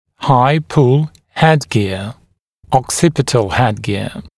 [haɪ-pul ‘hedgɪə] [ɔk’sɪpɪtl ‘hedgɪə][хай-пул’хэдгиа] [ок’сипитл ‘хэдгиа]высокая (теменная) внеторовая тяга